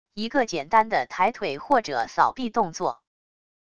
一个简单的抬腿或者扫臂动作wav音频